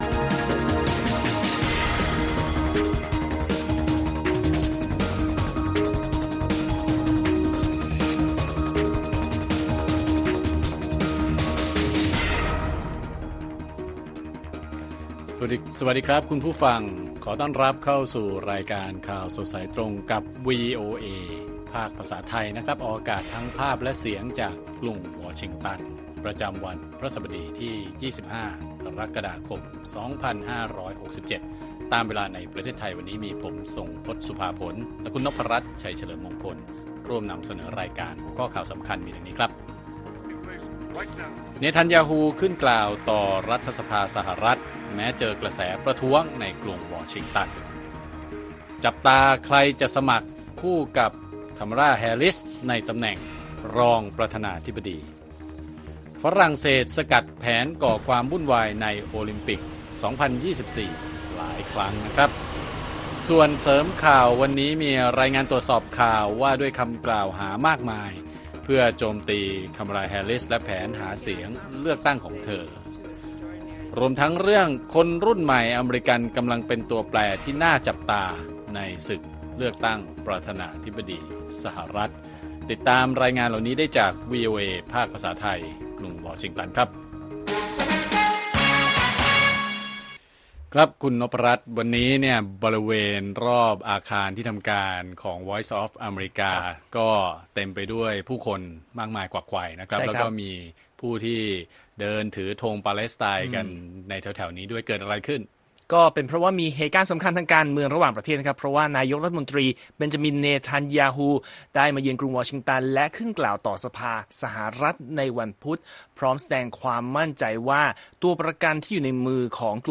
ข่าวสดสายตรงจากวีโอเอ ไทย ประจำวันที่ 25 กรกฎาคม 2567